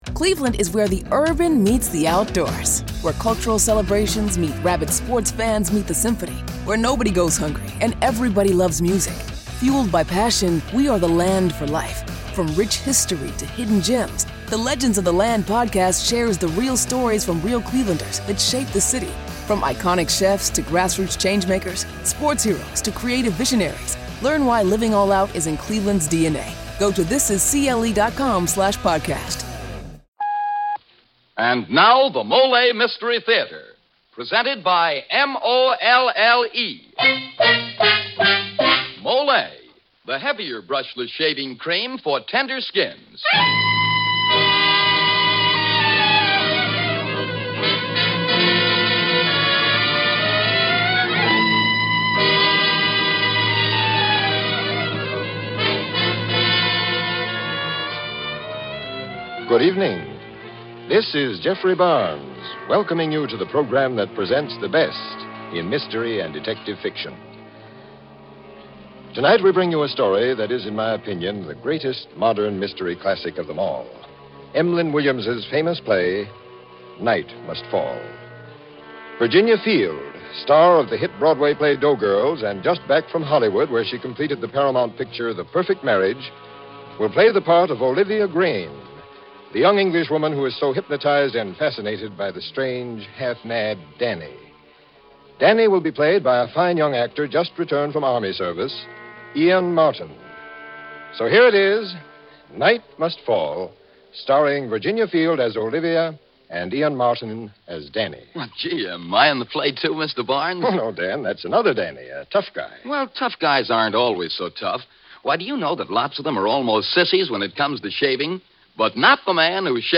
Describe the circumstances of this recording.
On this episode of the Old Time Radiocast we present you with two stories from the classic radio program Mollé Mystery Theatre.